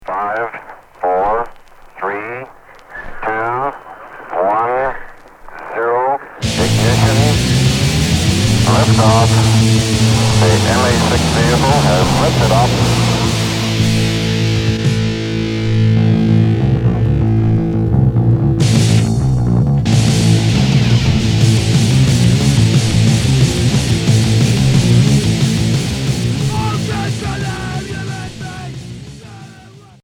Punk hardcore